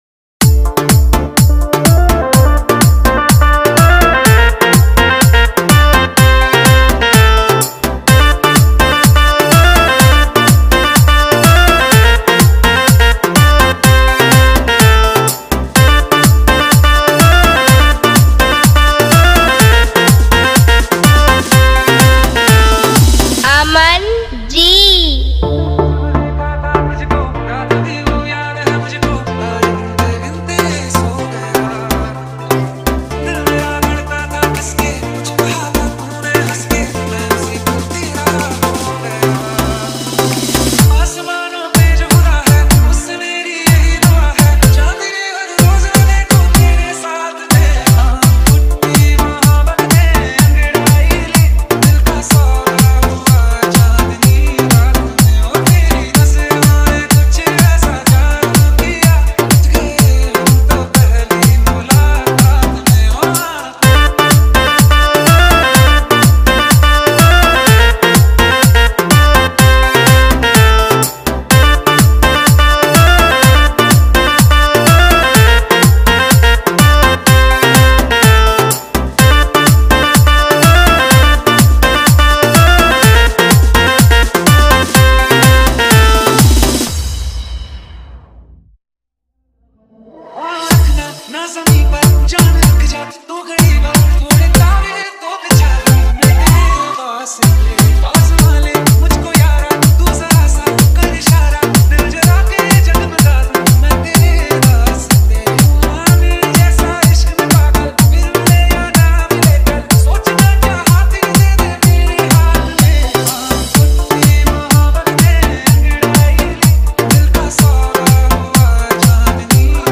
Hindi Dj Songs